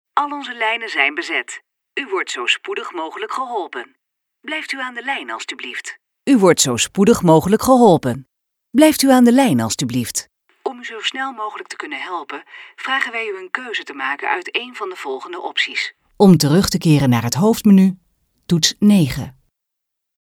IVR
Haar communicatie stijl is nuchter, trefzeker, warm en betrokken; to-the-point.
Accentloos, helder en met de juiste toon voor jouw doelgroep.